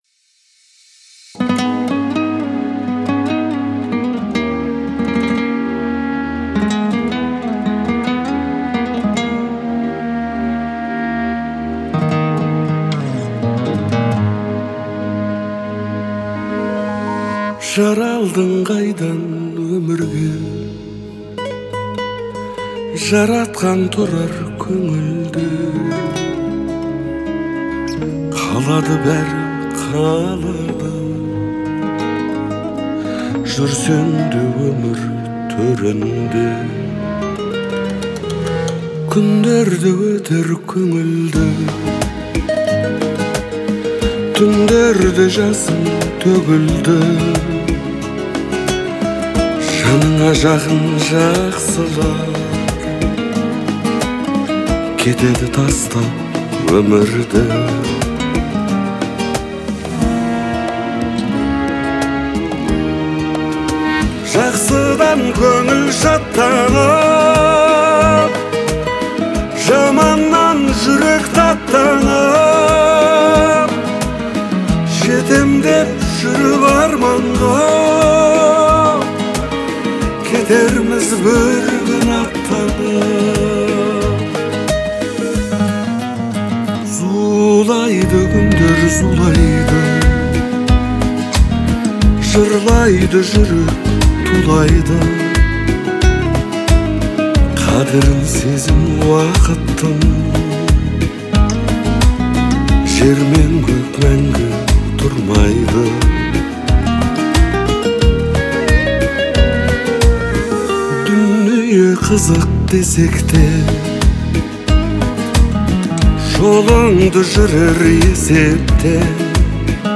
это трогательная композиция в жанре поп
мягкими мелодиями
выразительным вокалом